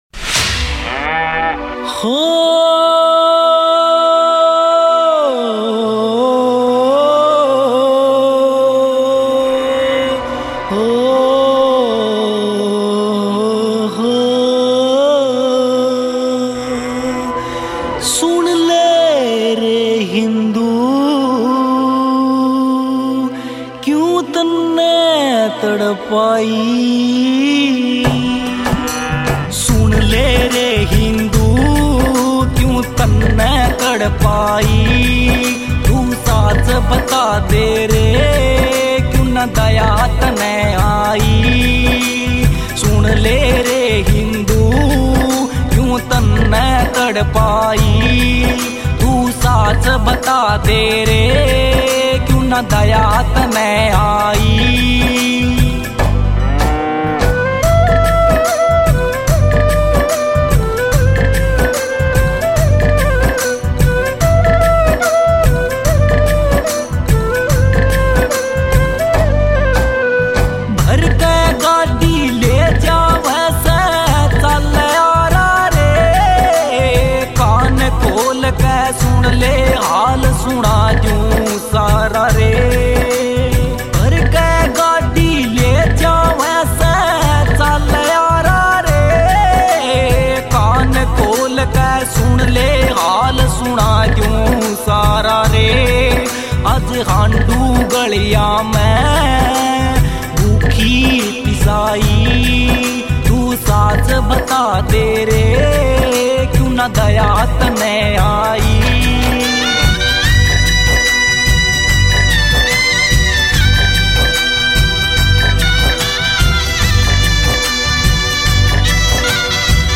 » Haryanvi Songs